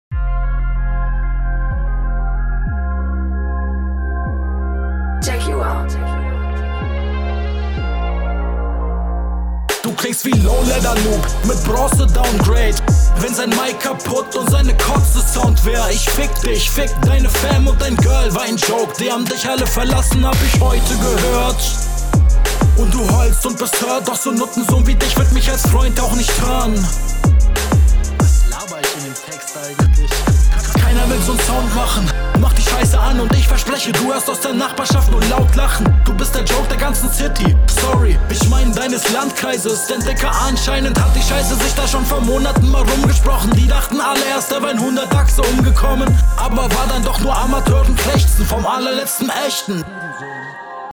Sehr cooler beat und du kommst auch echt nice auf dem, der flow gefällt mir …